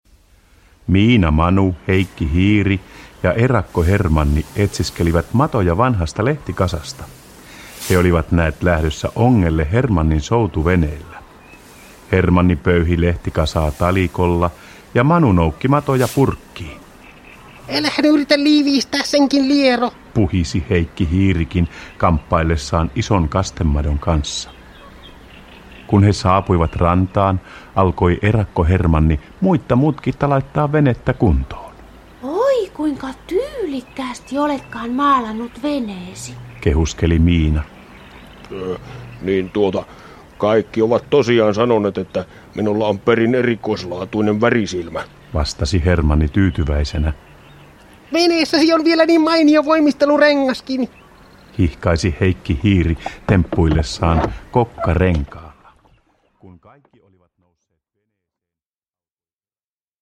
Miinan ja Manun onkimatka – Ljudbok – Laddas ner